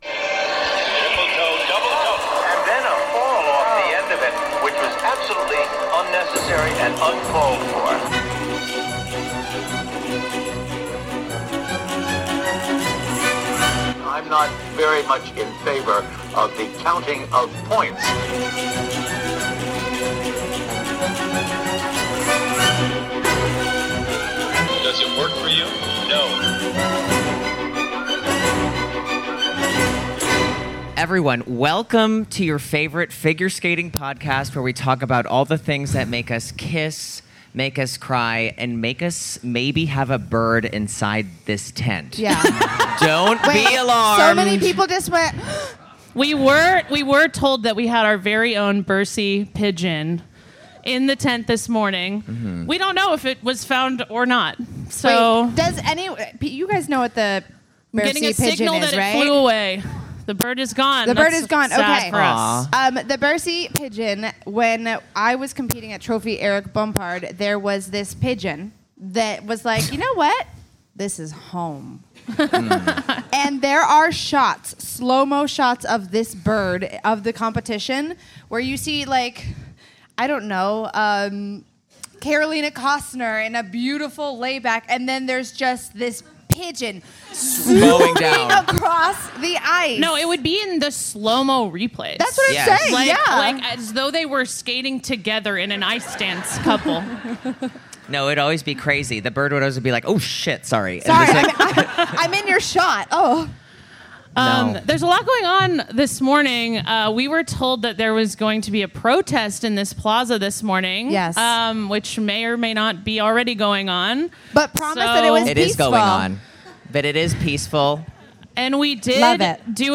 1 Episode 109: Live Show in St. Louis DAY ONE 1:00:46
(Have no fear as the audio dramatically gets better 5 minutes in!!!